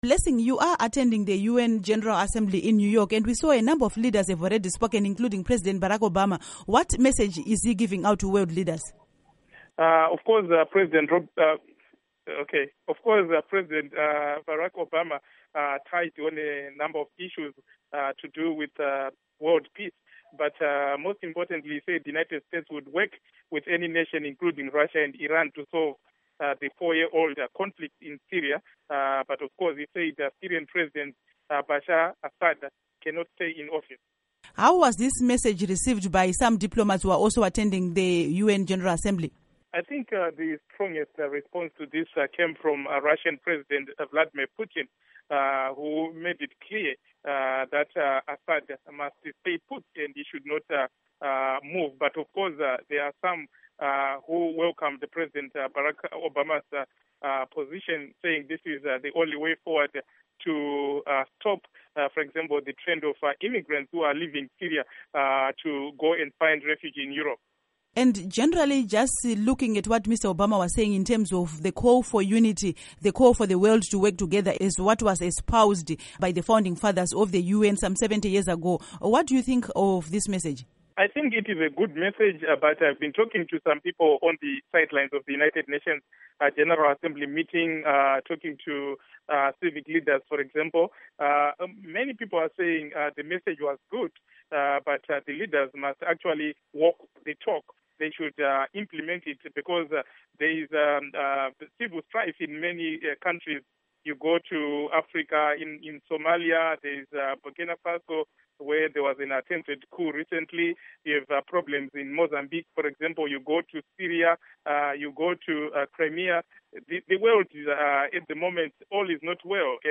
Speaks From UN General Assembly in New York